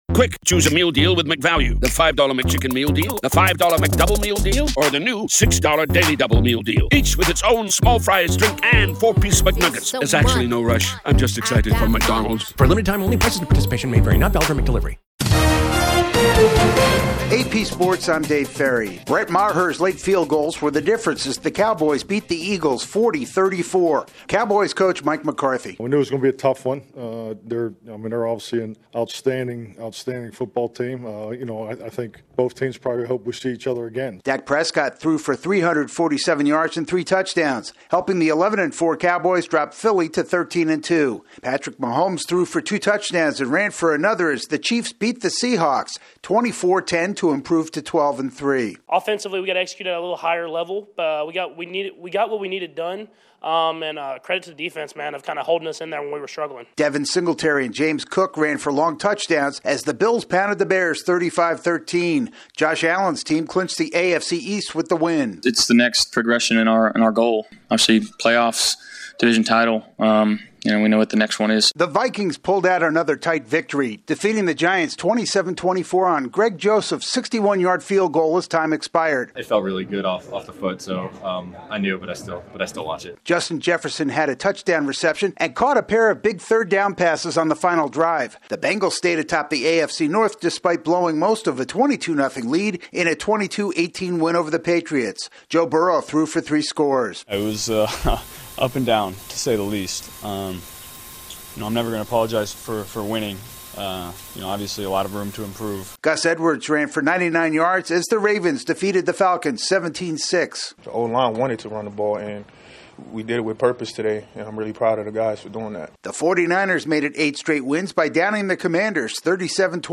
Sports News from the Associated Press / The latest in sports